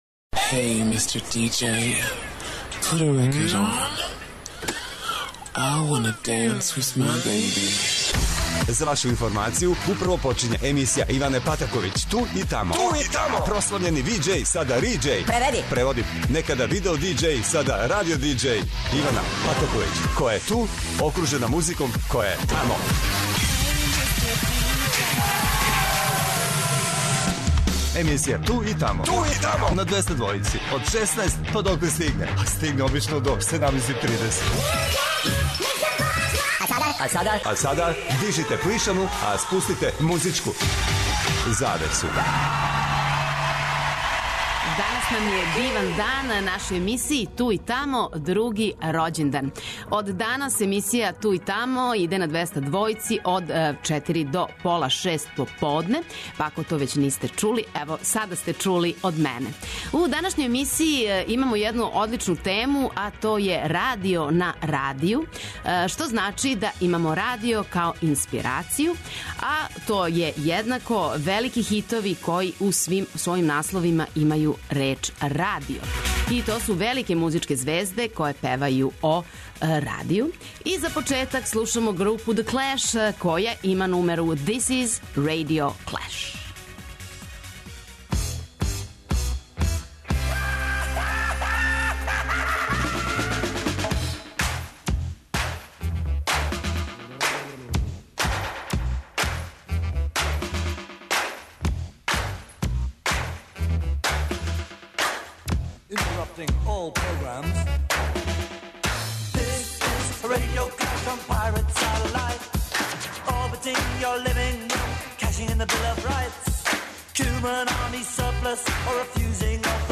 Нов термин, али стари концепт - необичне, распеване и занимљиве музичке теме.